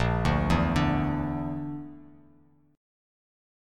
A#Mb5 chord